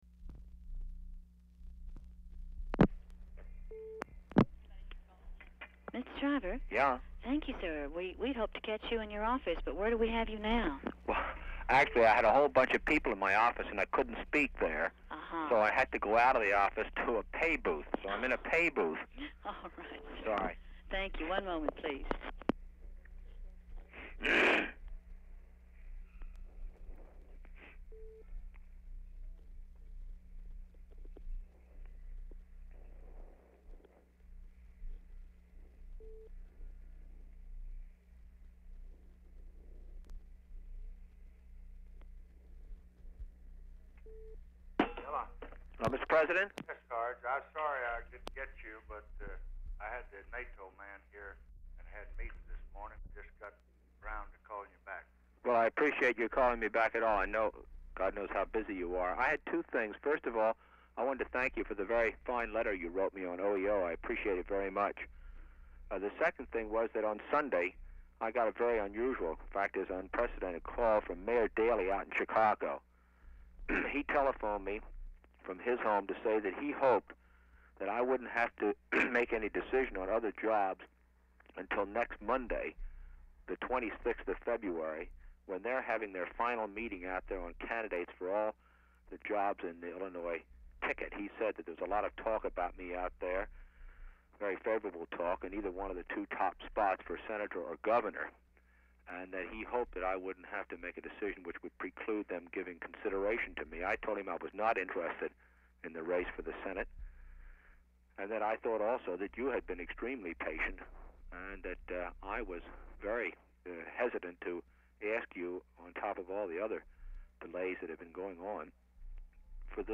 RECORDED ON REEL-TO-REEL TAPE, NOT DICTABELT; OFFICE SECRETARY SPEAKS WITH SHRIVER BRIEFLY BEFORE SHRIVER SPEAKS WITH LBJ; SHRIVER TELLS HER HE IS CALLING FROM PAY TELEPHONE
Specific Item Type Telephone conversation